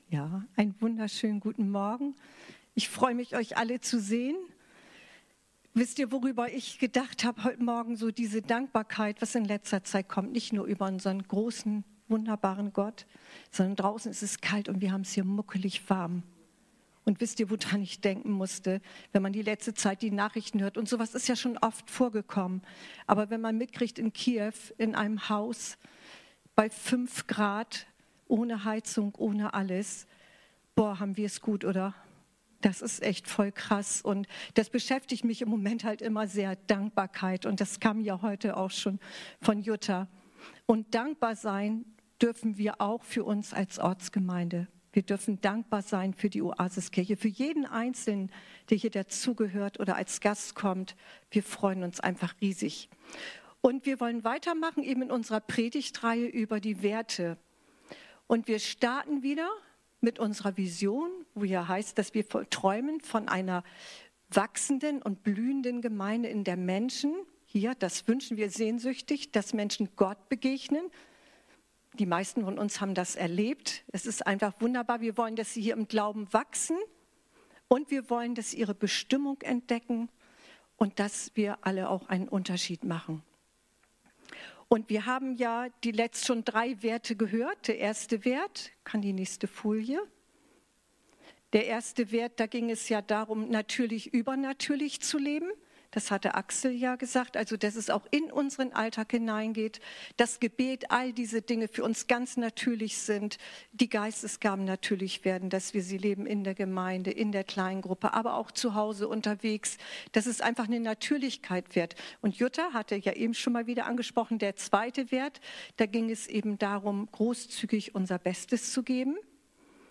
Eine predigt aus der predigtreihe "Unsere Werte."